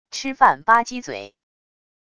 吃饭吧唧嘴wav音频